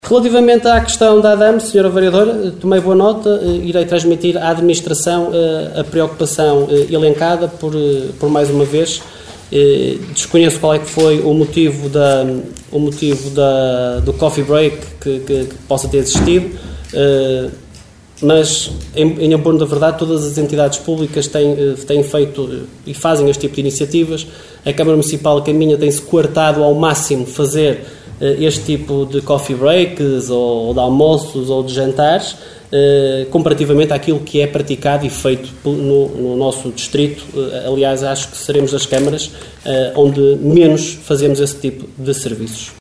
Excertos da última reunião camarária, realizada no passado dia 19 de fevereiro, no Salão Nobre dos Paços do Concelho.